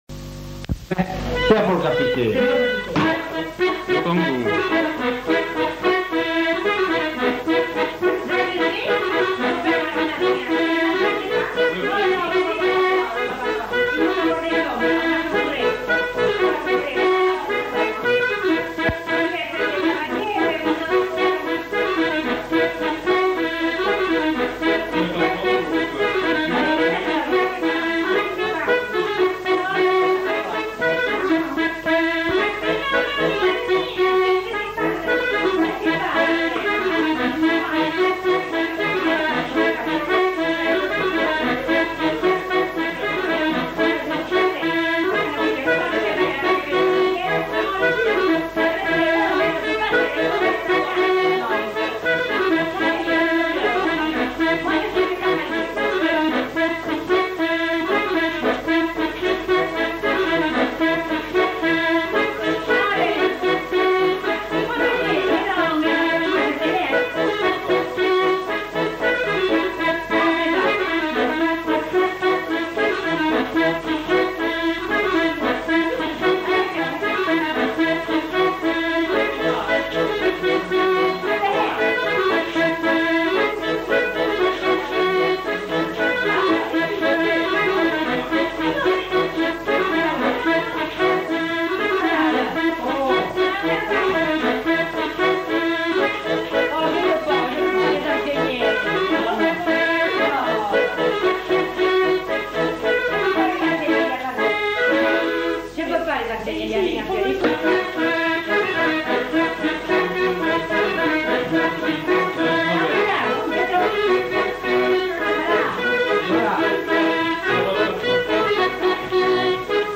Aire culturelle : Grandes-Landes
Lieu : Luxey
Genre : morceau instrumental
Instrument de musique : accordéon diatonique
Danse : congo